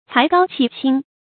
才高氣清 注音： ㄘㄞˊ ㄍㄠ ㄑㄧˋ ㄑㄧㄥ 讀音讀法： 意思解釋： 才：才能；氣：氣質。指才調高雅，氣質清新 出處典故： 唐 韓愈《與孟東野書》：「足下 才高氣清 ，行古道，處今世，無田而衣食。」